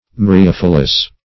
Search Result for " myriophyllous" : The Collaborative International Dictionary of English v.0.48: Myriophyllous \Myr`i*oph"yl*lous\, a. [Gr.